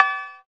808cowb.wav